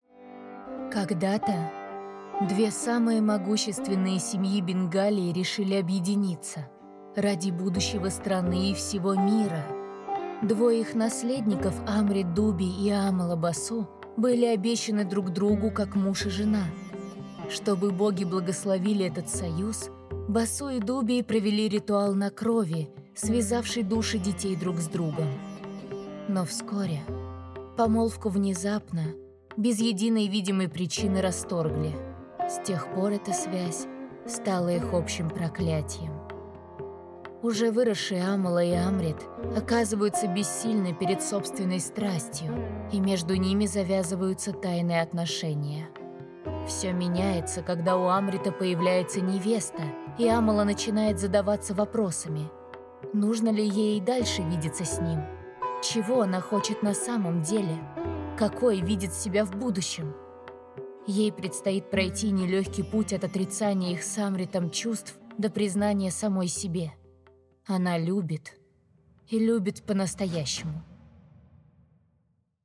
Аудиокнига Кали Зов Тьмы Проклятые — слушать онлайн на сайте RC Books